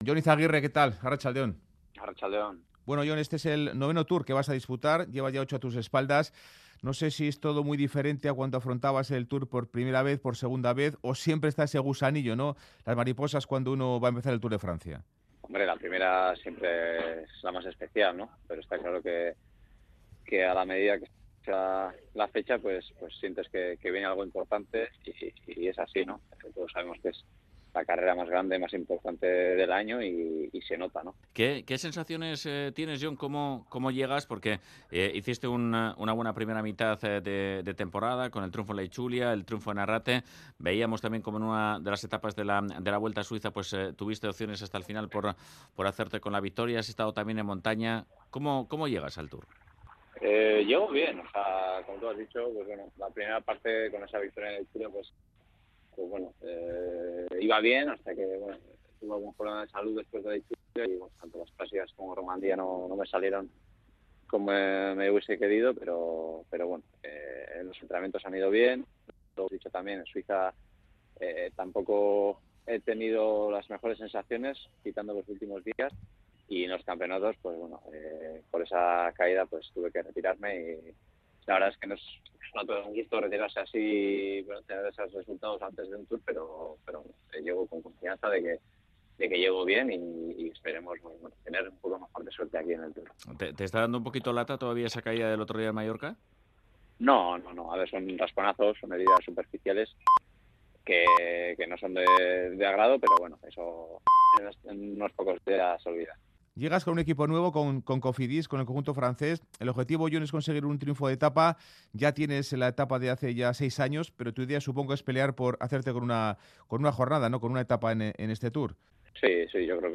Entrevista a Ion Izagirre